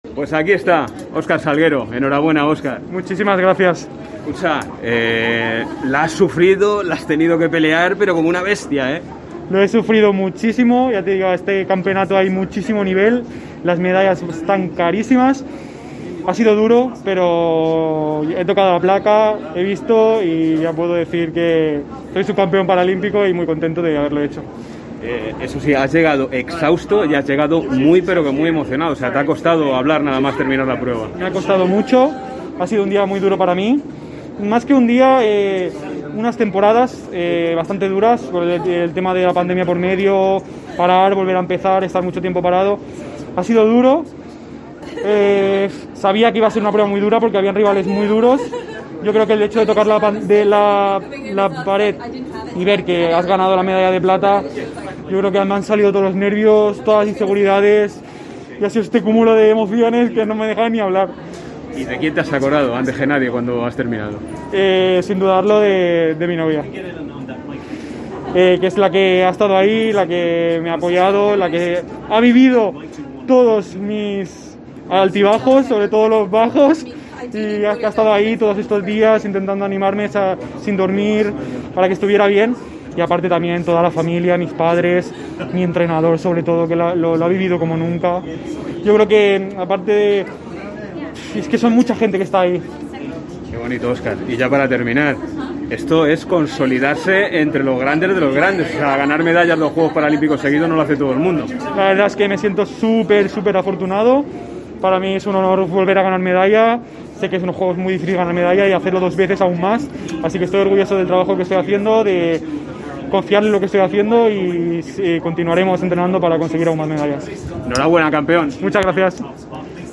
El nadador español mostró en COPE su felicidad tras ganar la plata.